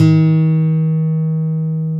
Index of /90_sSampleCDs/Roland L-CDX-01/GTR_Nylon String/GTR_Nylon Chorus
GTR NYLON20A.wav